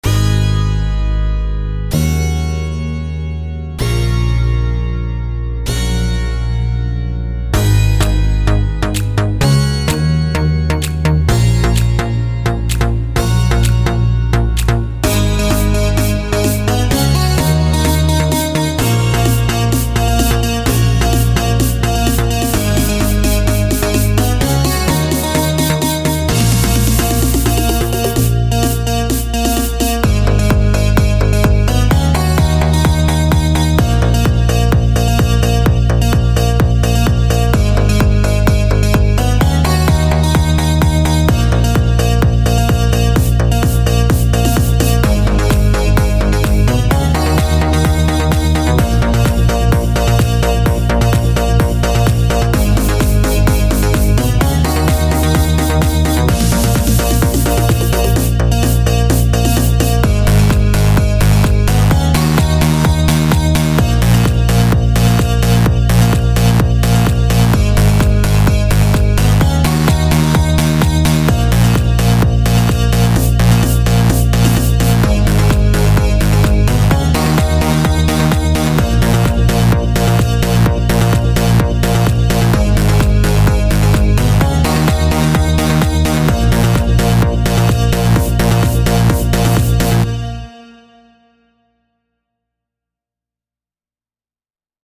אני עדיין לא סגור על הסאונד אז העלתי רק את הביט…